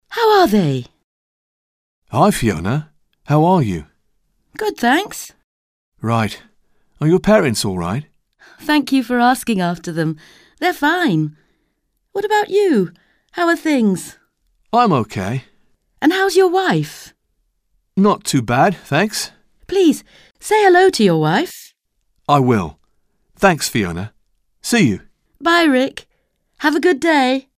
Dialogue - How are they?